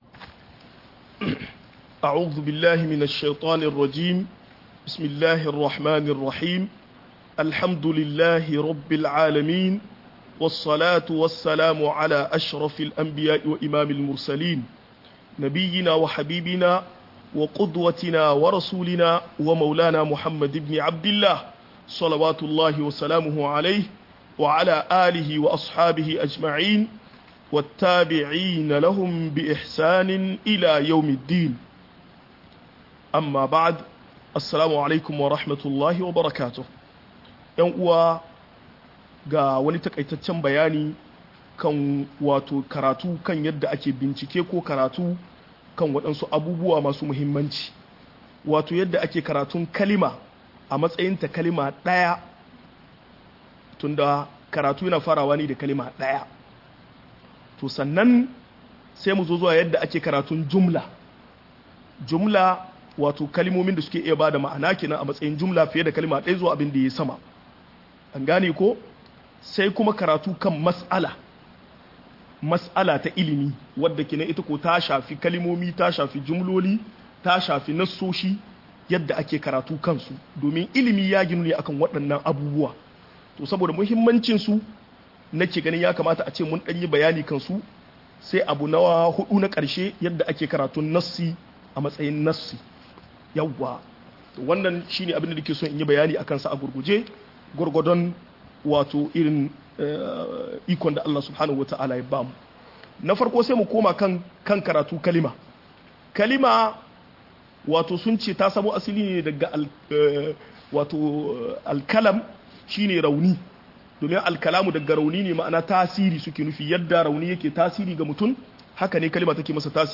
Yadda ake karatun kalmomi da jumloli - MUHADARA